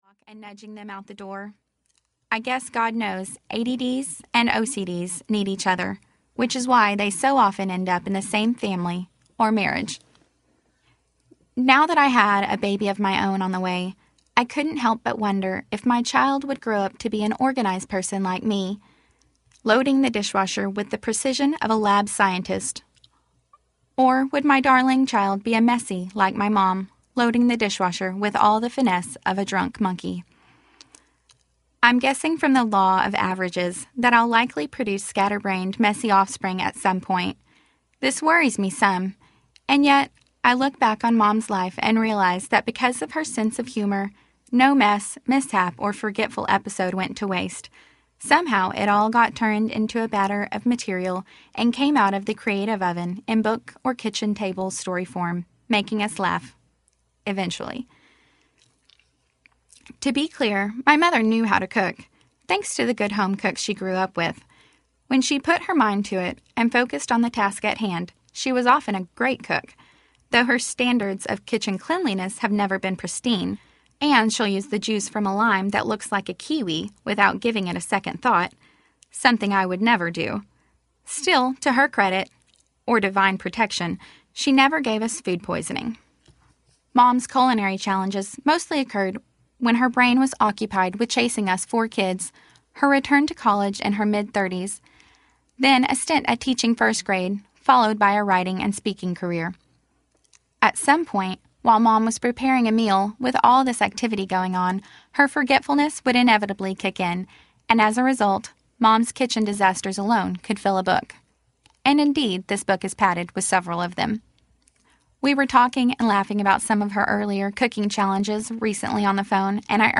We Laugh, We Cry, We Cook Audiobook
5 Hrs. – Unabridged